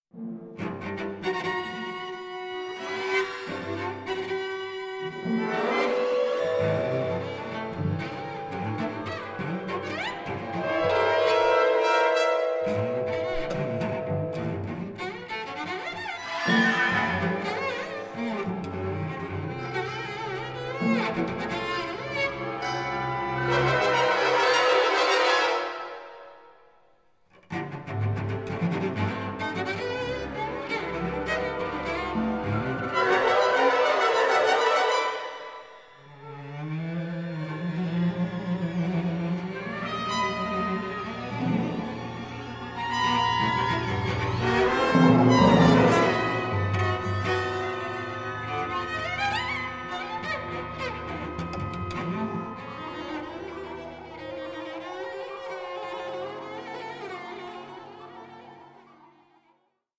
Concerto for Violoncello and Orchestra
In a live performance